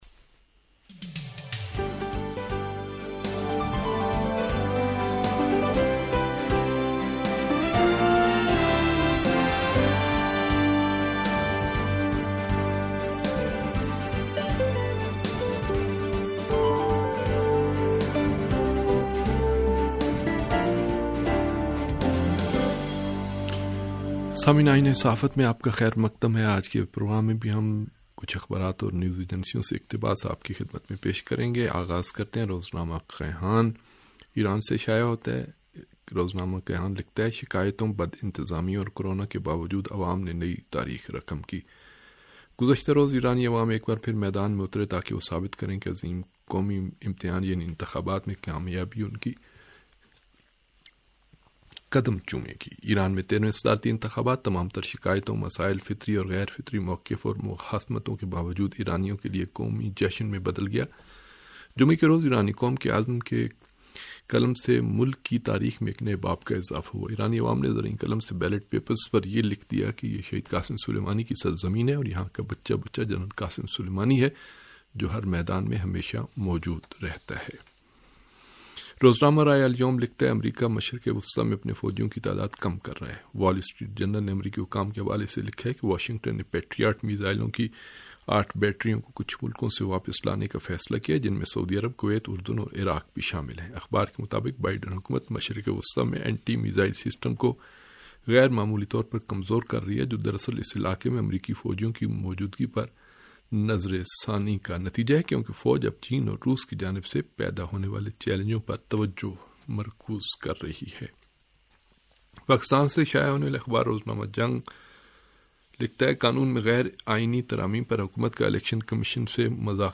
ریڈیو تہران کا اخبارات کے جائزے پرمبنی پروگرام آئینہ صحافت